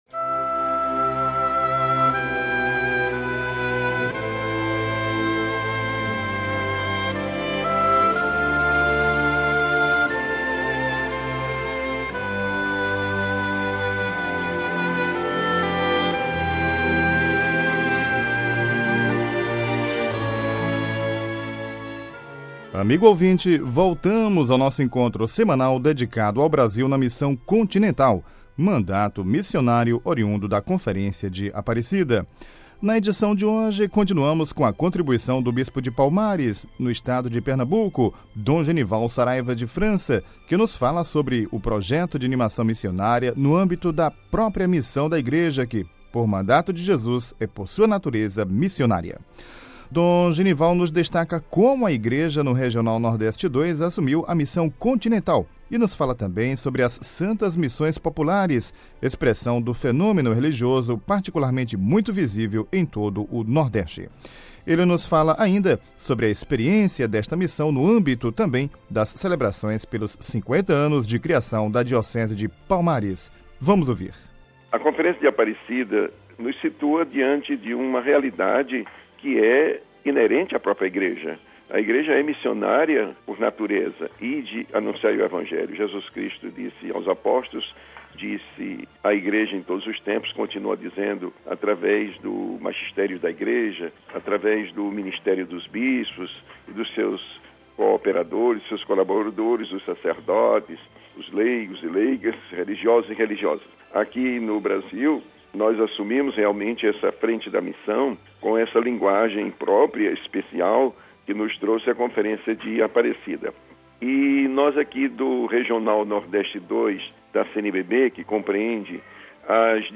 Na edição de hoje continuamos com a contribuição do bispo de Palmares - PE, Dom Genival Saraiva de França, que nos fala sobre projeto de animação missionária no âmbito da própria missão da Igreja que, por mandato de Jesus, é por sua natureza missionária.